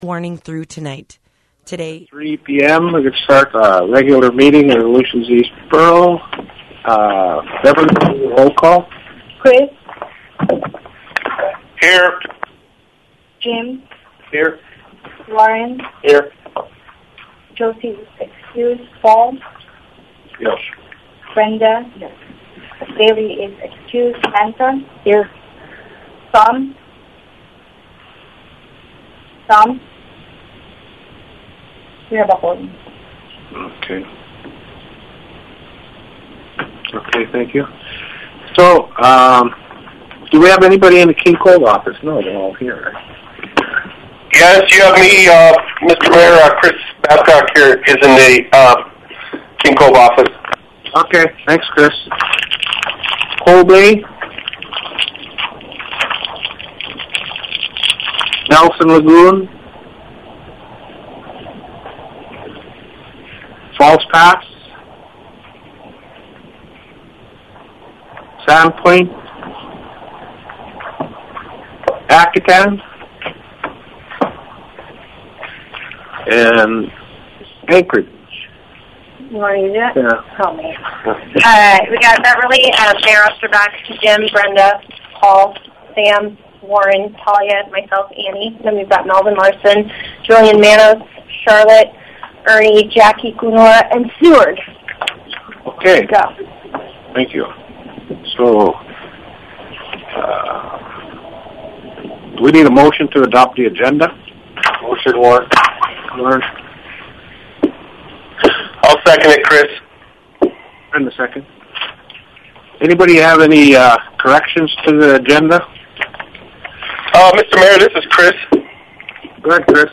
The Aleutians East Borough Assembly will hold a special meeting on Thursday, May 26th, 2022 at 3:00 PM. KSDP will air the meeting live & archive the audio here.